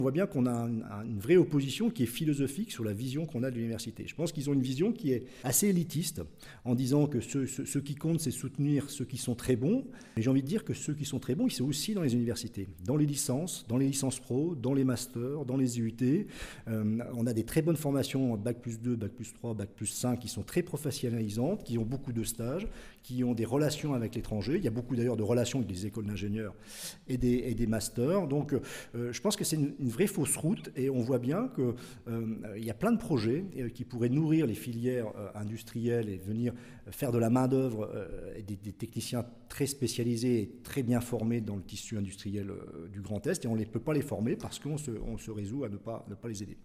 Selon lui, la collectivité régionale devrait aider tous les bons projets et pas seulement ceux issus des grandes écoles, il s’explique.